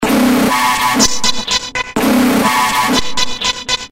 描述：鼓和基地循环，带有语音循环，电子合成器基座
Tag: kickdrum 电子 旋律 合成器 TECHNO 循环 舞蹈 硬的 神志恍惚 击败 进展 低音 俱乐部